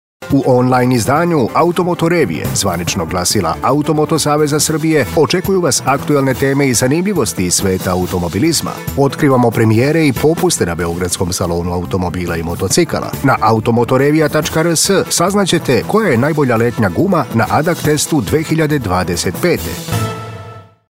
Časopis – Snimanje offa, brz tempo čitanja kratkih tv najava za razne namene 10,15,20 sekundi
citanje-teksta-naracije-glas-dinamican-brz-izgovor-za-srpske-video-reklame.mp3